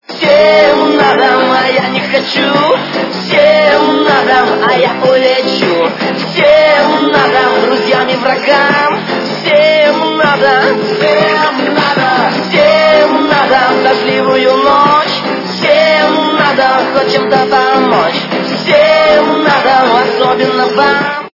- украинская эстрада
При заказе вы получаете реалтон без искажений.